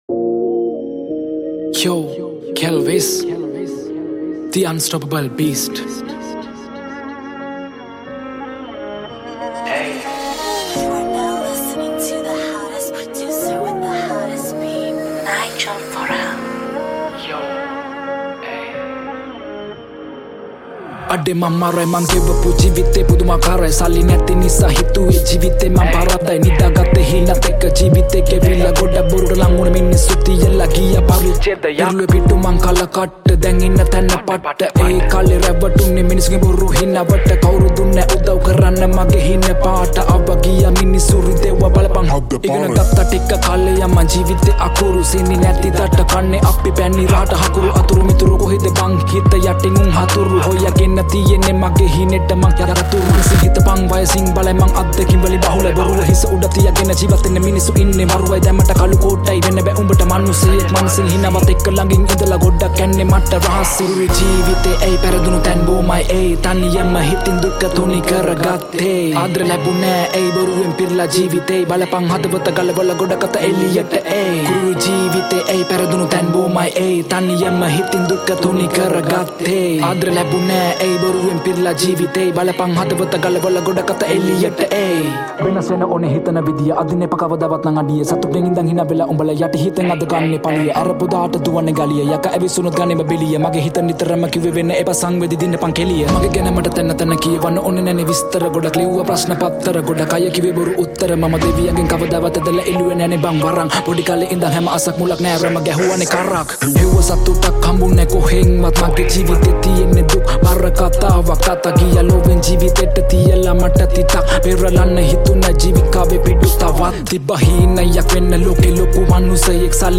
High quality Sri Lankan remix MP3 (3.9).
Rap